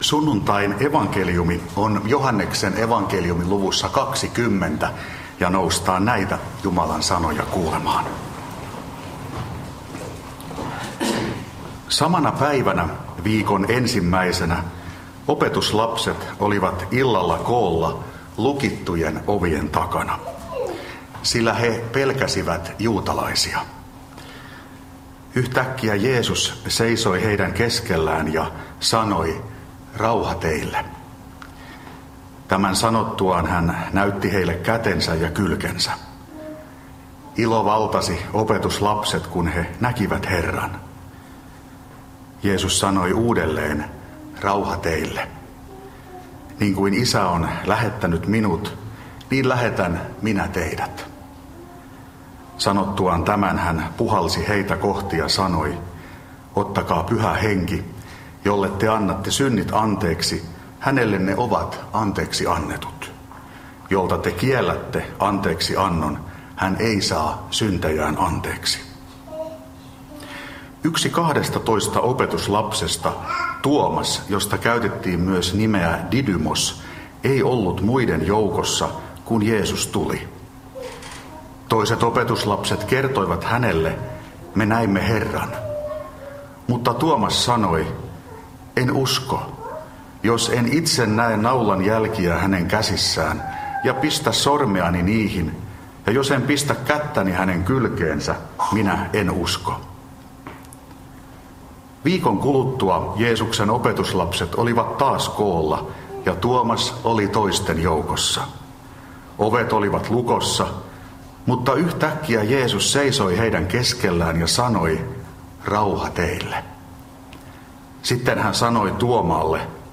saarna Karkussa 1. sunnuntaina pääsiäisestä Tekstinä Joh. 20:19–31